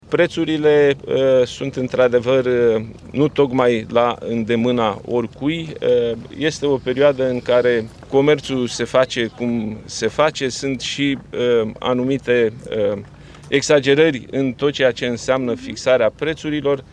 Aflat miercuri într-o vizită în județul Bihor, premierul Nicolae Ciucă a fost întrebat dacă a mers recent la piață și cum i se par prețurile: